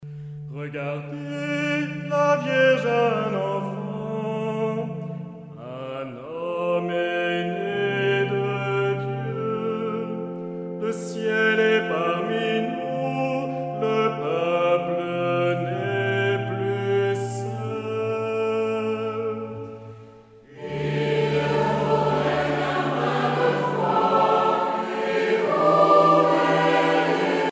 Maîtrise
Chants traditionnels